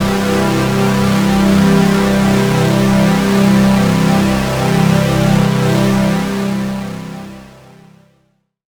36ad01pad-cM.wav